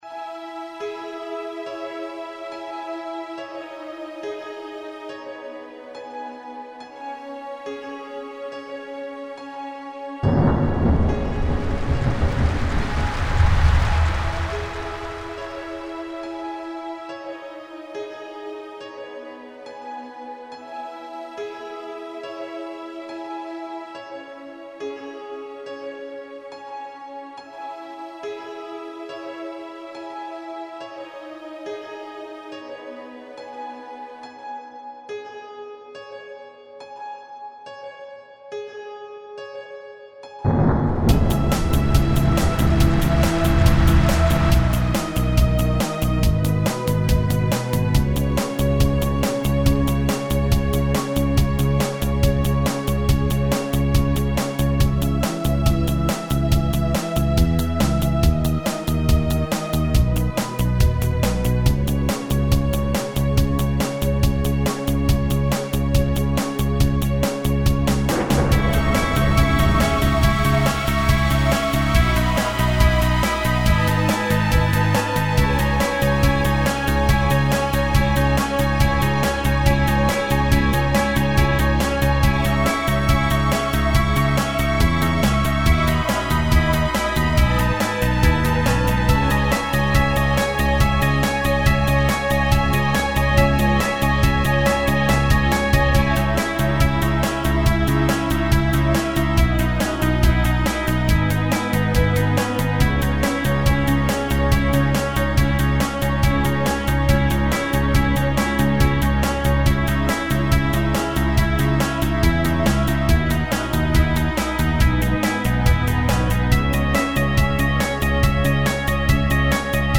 music_goth.mp3